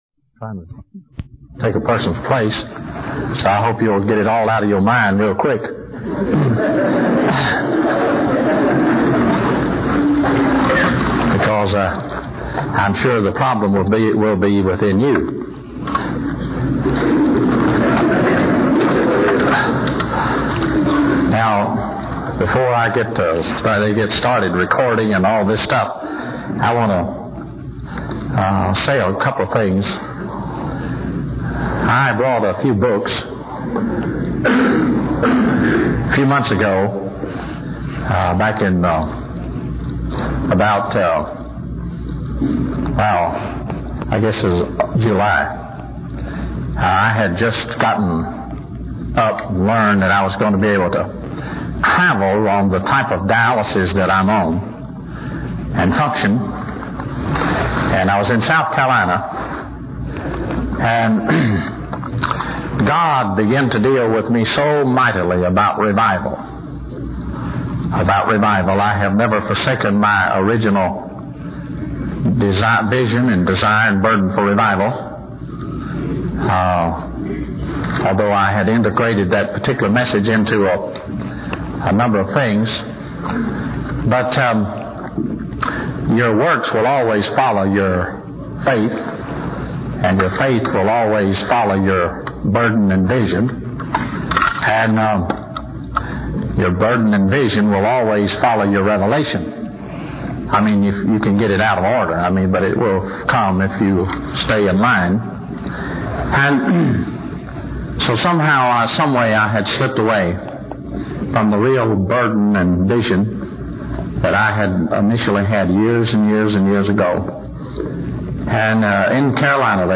In this sermon, the preacher discusses a conversation between Jesus and his disciples.